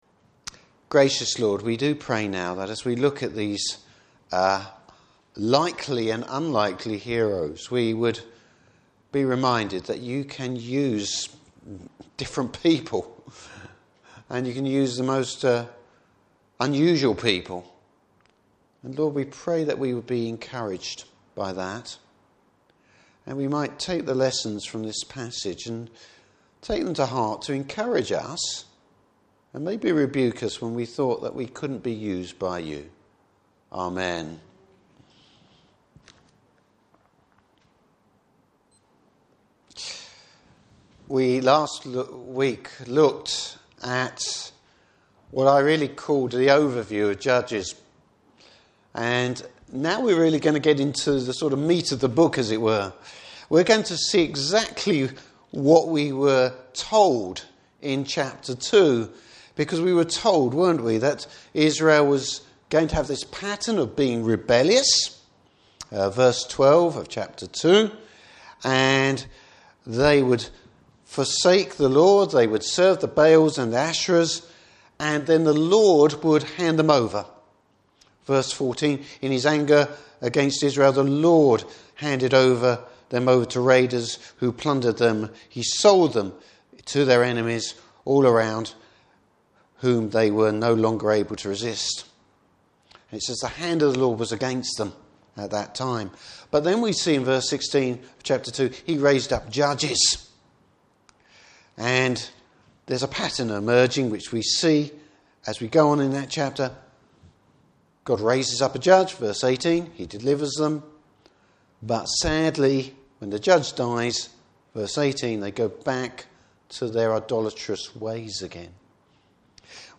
Service Type: Evening Service Bible Text: Judges 3:7-31.